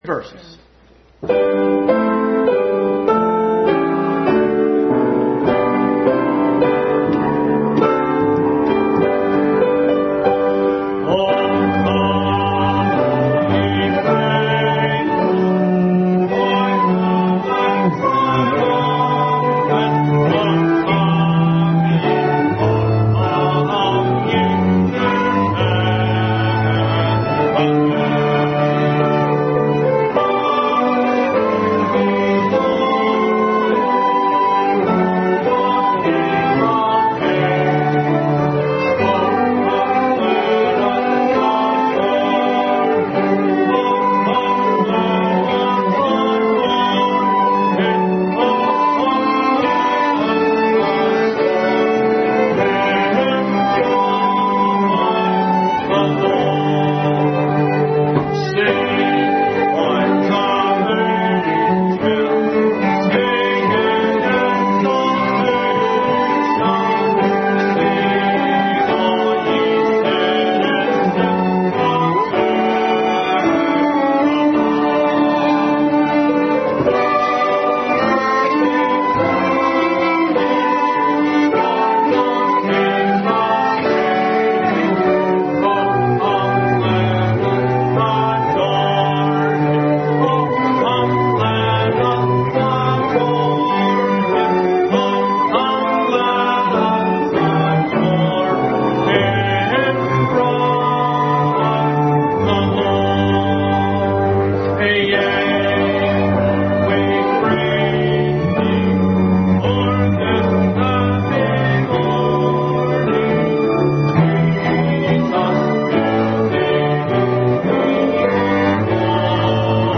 2022 Christmas Program